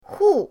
hu4.mp3